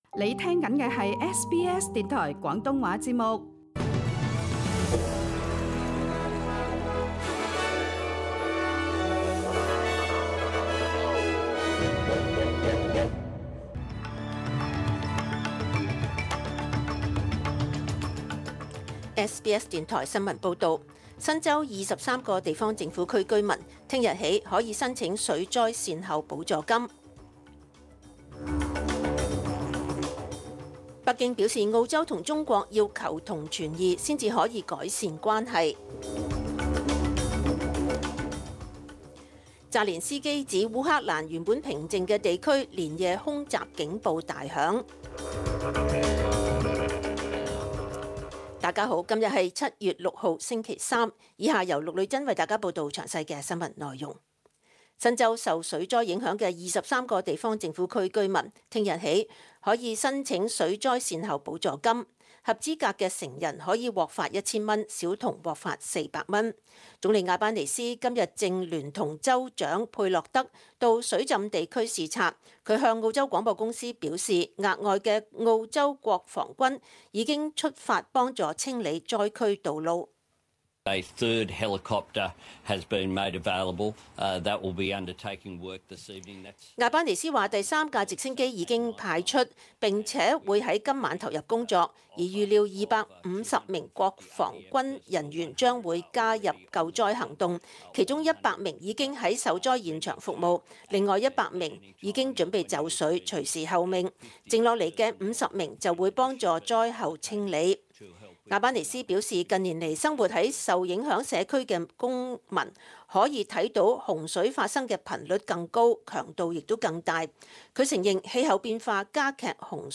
SBS 中文新闻 （7月6日）
SBS 廣東話節目中文新聞 Source: SBS Cantonese